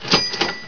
chaching.wav